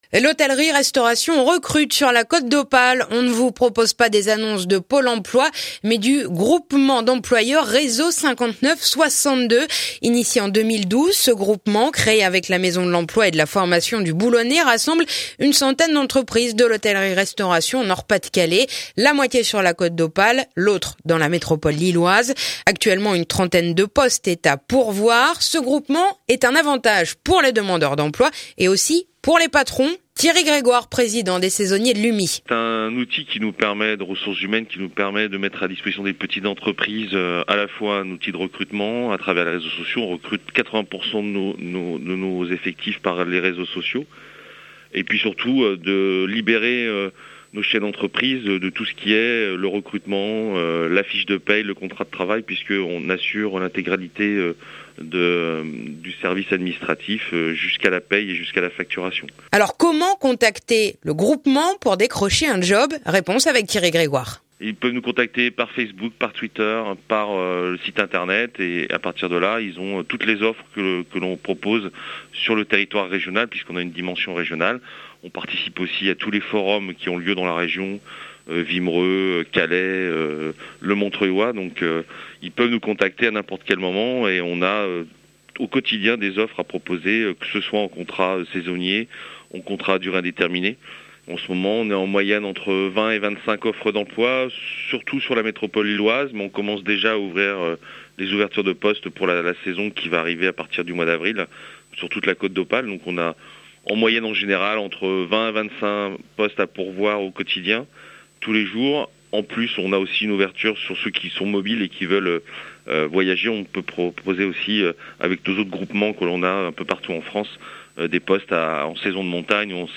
reportage et explications